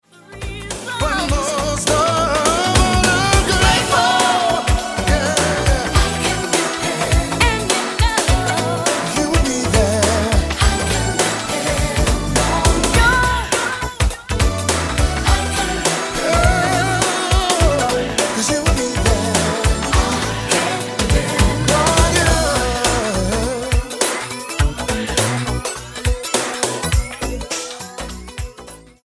R&B gospel duo